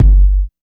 81 KICK 3.wav